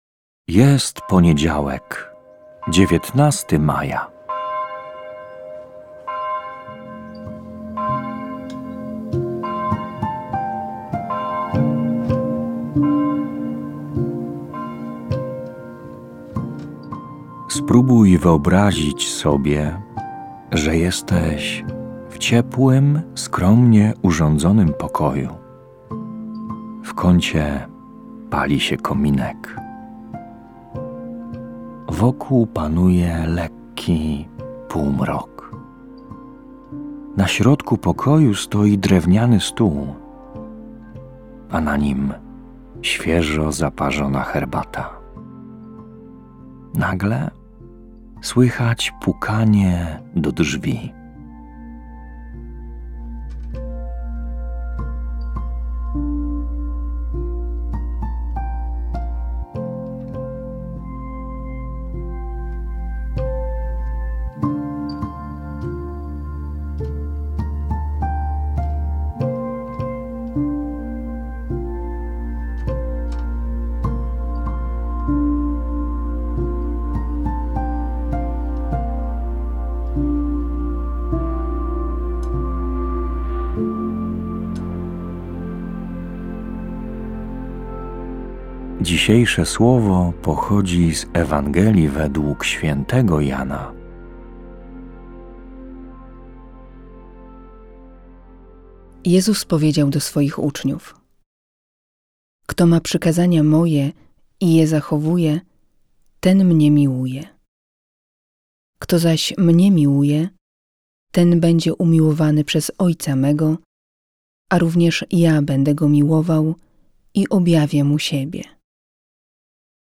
Kazanie ks. Proboszcza, wygłoszone w Boże Narodzenie 2023r.